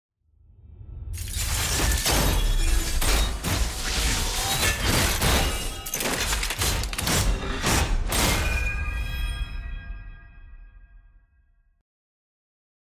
sfx-championmastery-levelup-8.ogg